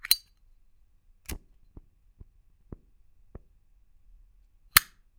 LIGHTER 2 -S.WAV